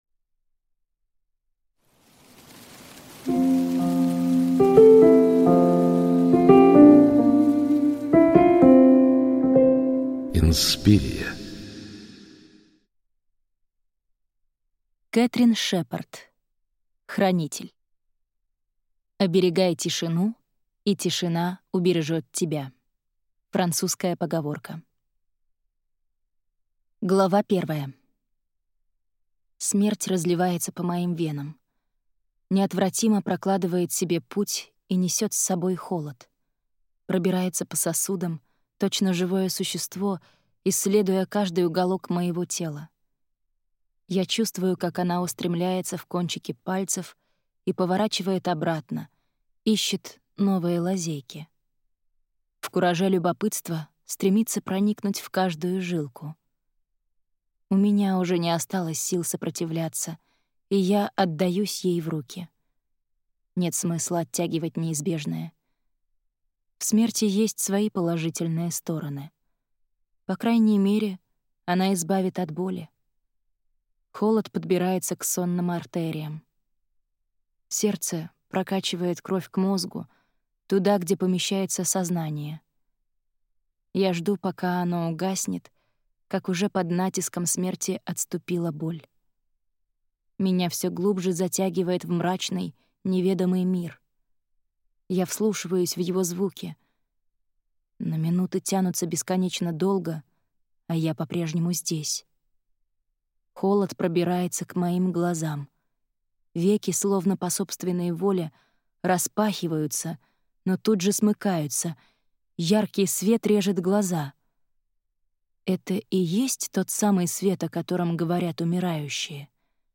Аудиокнига Хранитель | Библиотека аудиокниг
Прослушать и бесплатно скачать фрагмент аудиокниги